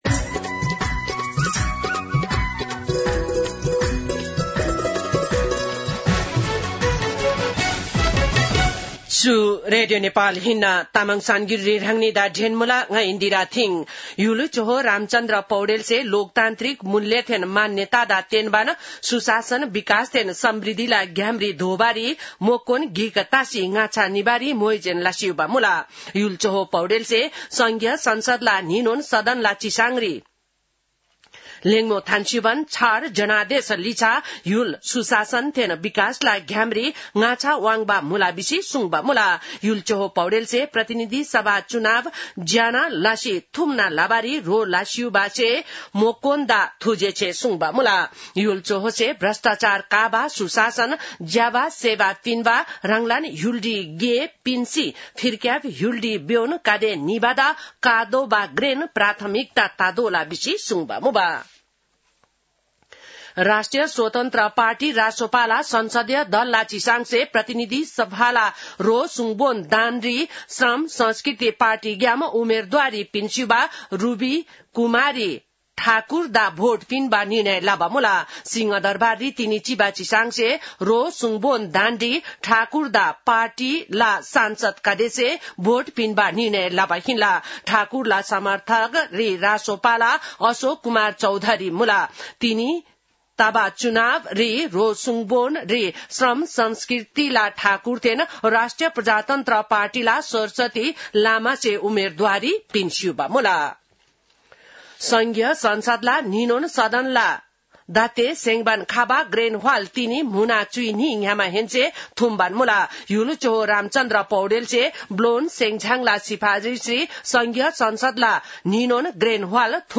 तामाङ भाषाको समाचार : २७ चैत , २०८२